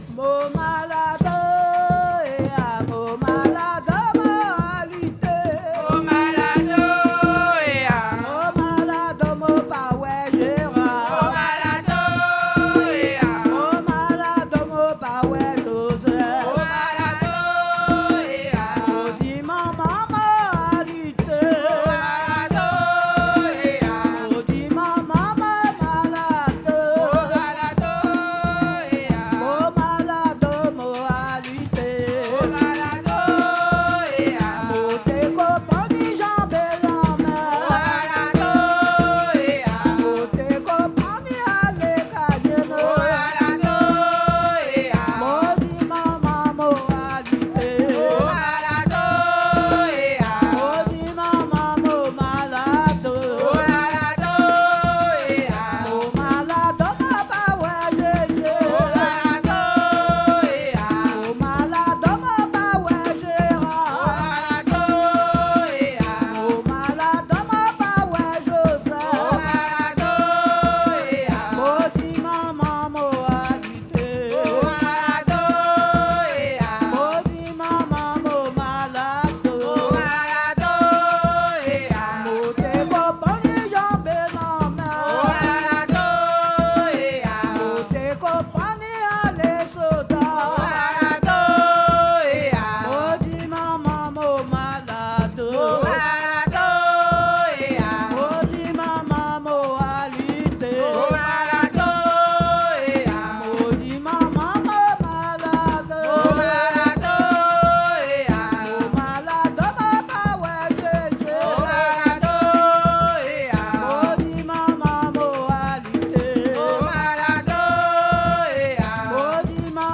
Saint-Georges-de-l'Oyapoc
danse : kasékò (créole)
Pièce musicale inédite